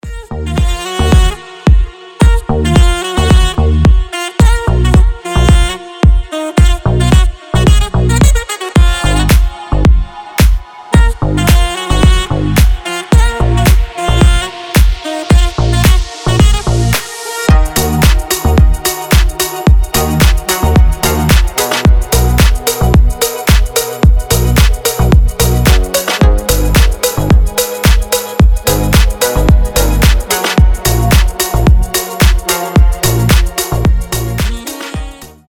deep house
без слов
красивая мелодия
Саксофон
Звонок для любителей саксофона